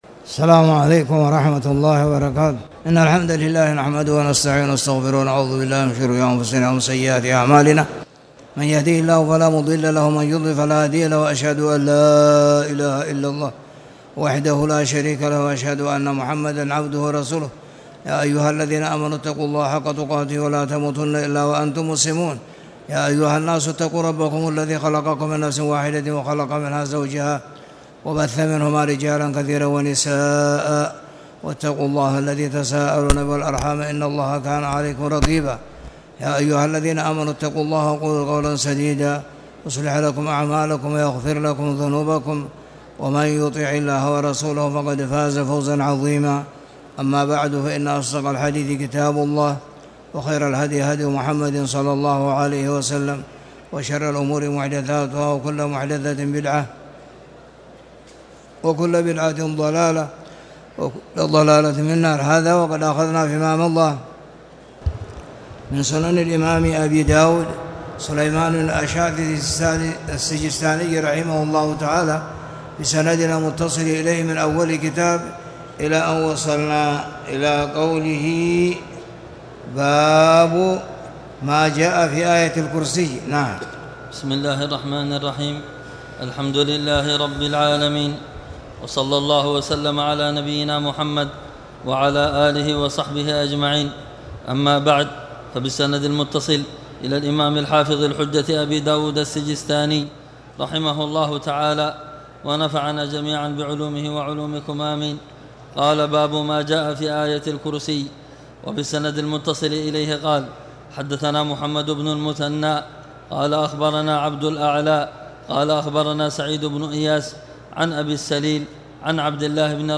تاريخ النشر ٢٩ جمادى الآخرة ١٤٣٨ هـ المكان: المسجد الحرام الشيخ